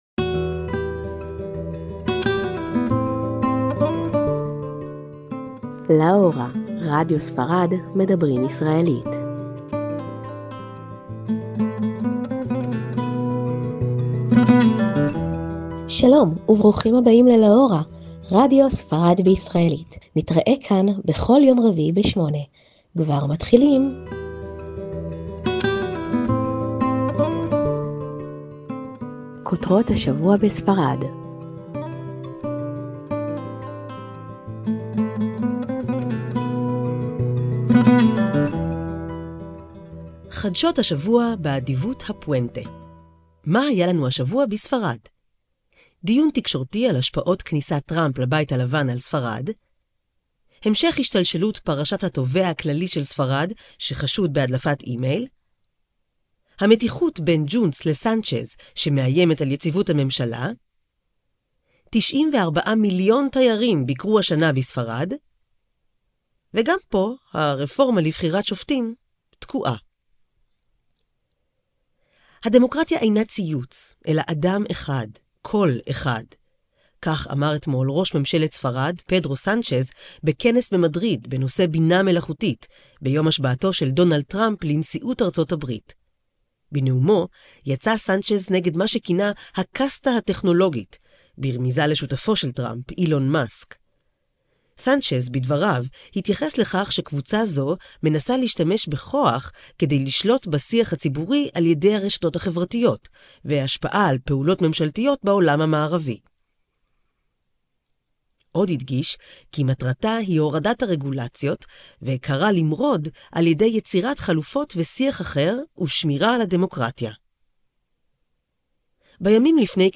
“LA HORA”: MEDABRÍM ISRAELÍT –״לה הורה״ - מיזם רדיו לטובת הישראלים בספרד.